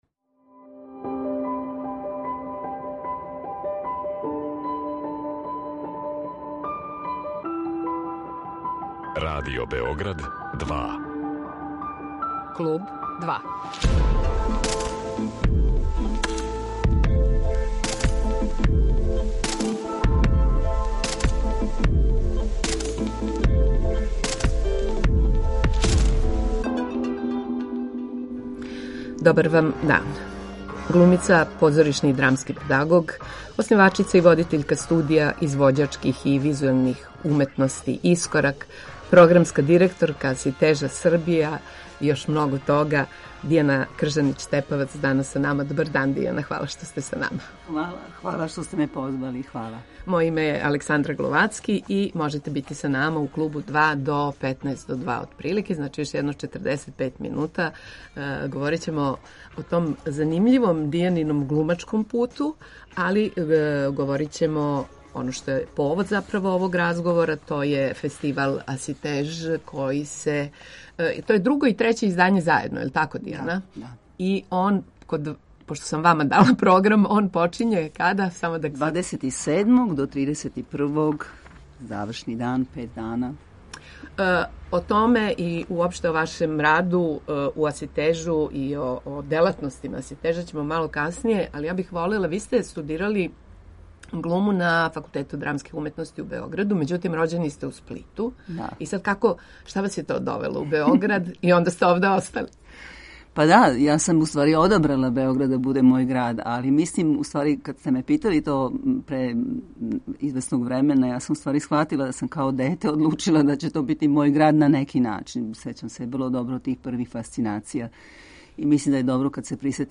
Гошћа је глумица